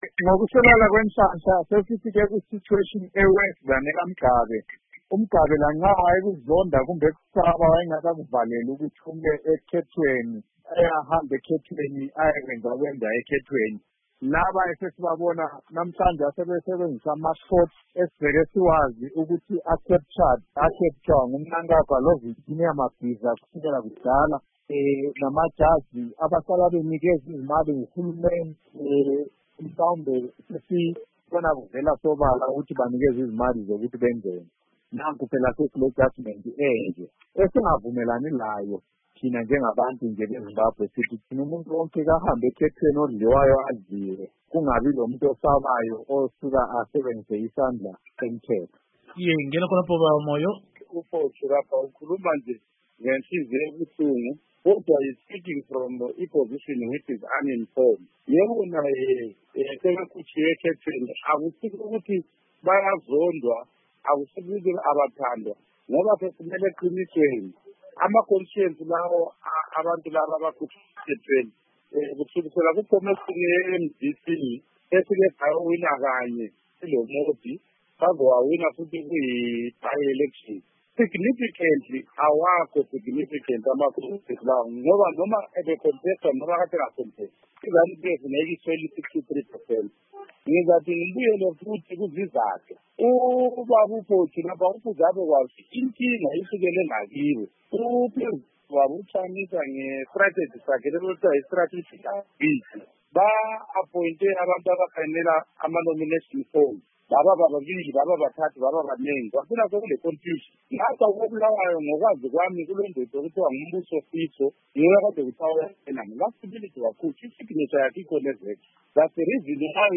Ingxoxo Esiyenze